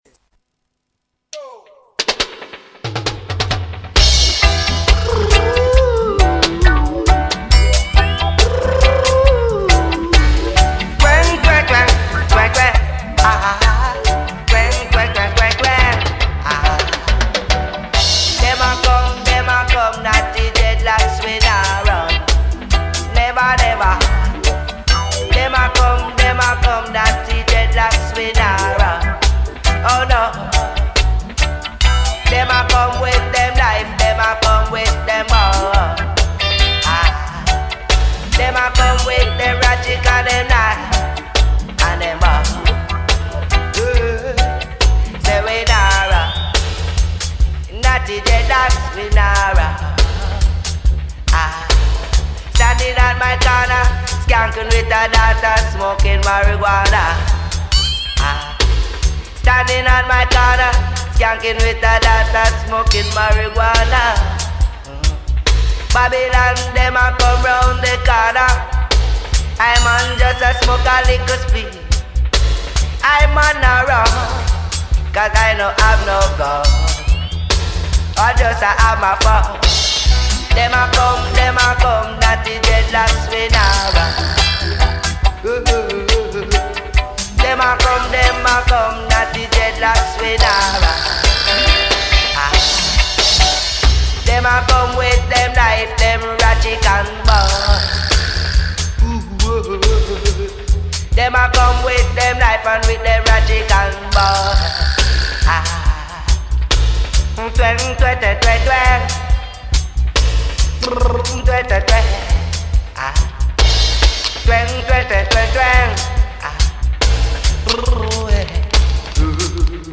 strictly 70s & 80s Roots music